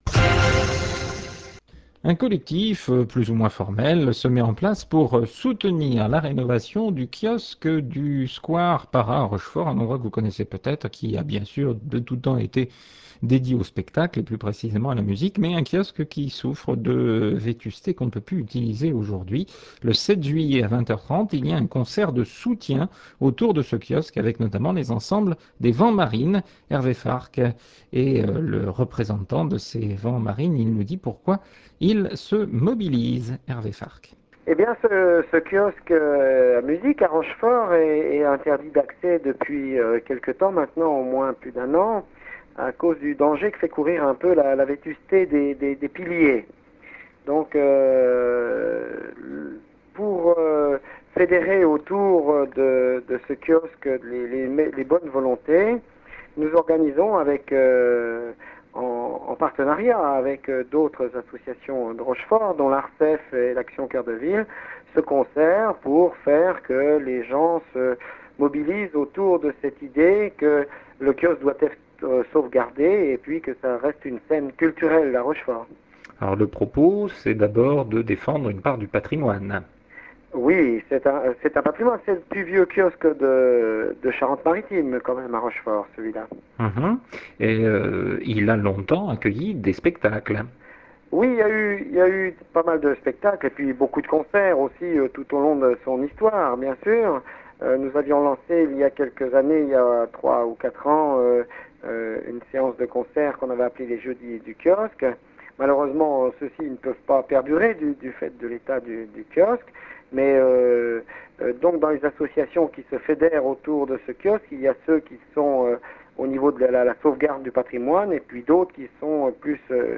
On en parle dans le poste (5 Juillet 2006)
Interventions dans les journaux de